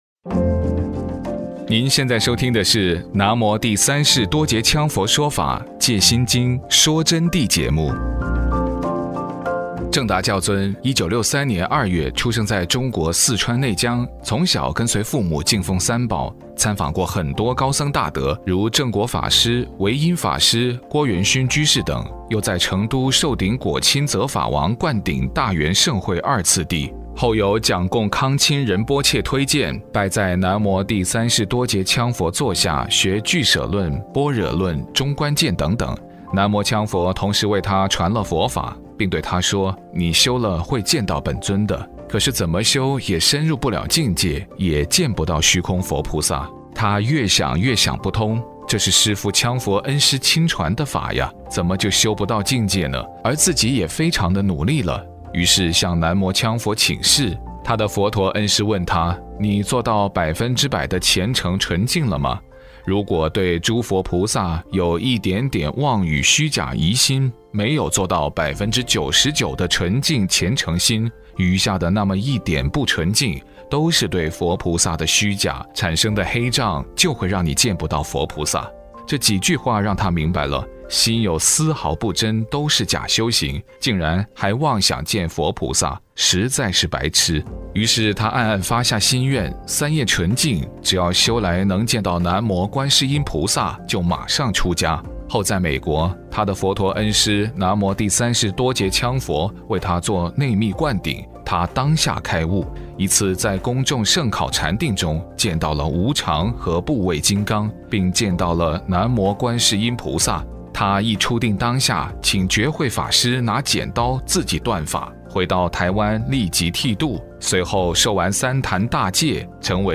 佛弟子访谈（55）